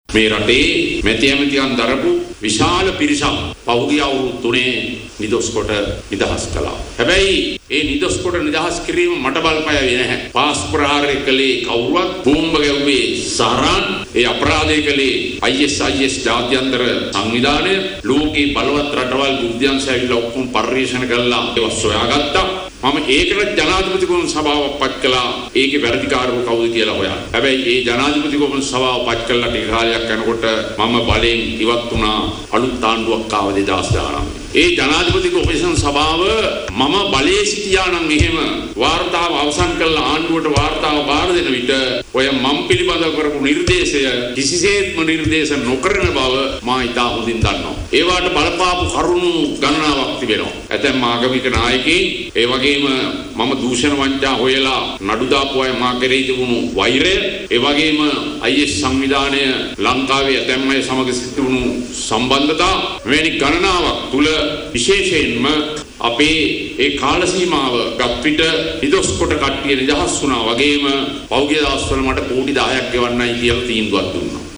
ඔහු මේ බව පැවසුවේ කළුතර පැවති ජන හමුවකට එක්වෙමිනි.